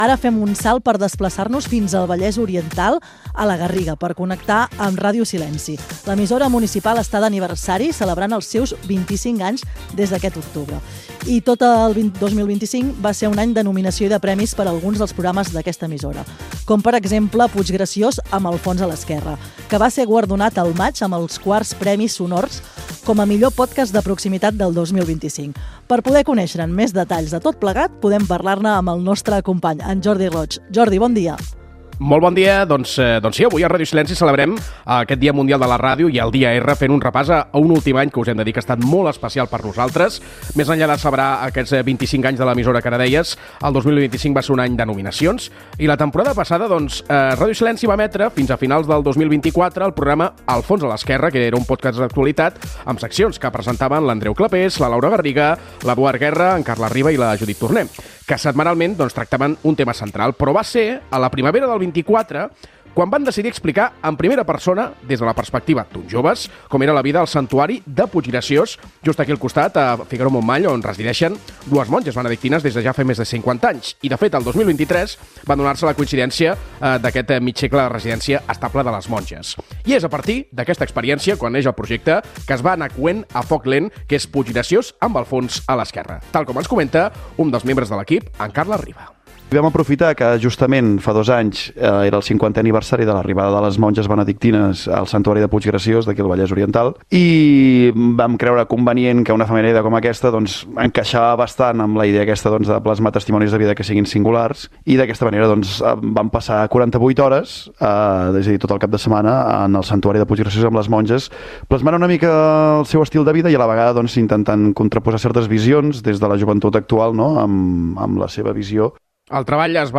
Conversa sobre el pòdcast "Puiggràcios amb Al fons a l'esquerra", sobre les monges benedictines del santuari.
Fragment extret de l'arxiu sonor de La Xarxa.